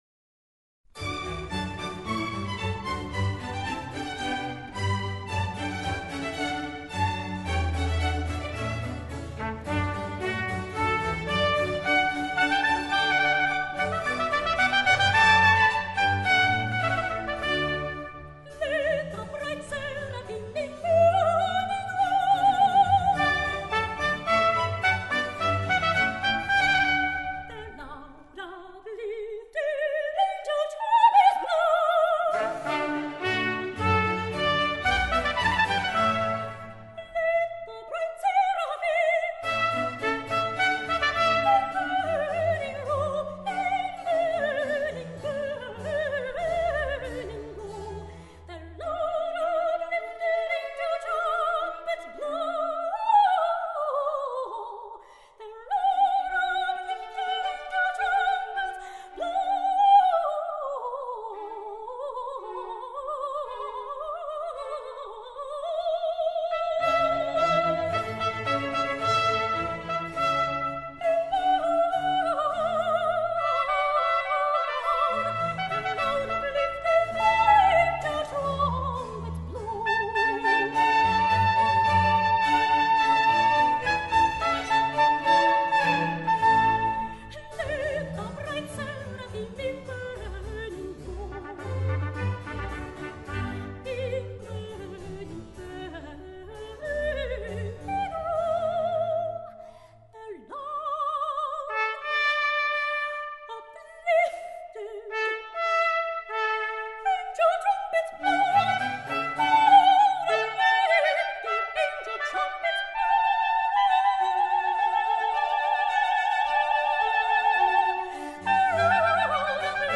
风格：古典/发烧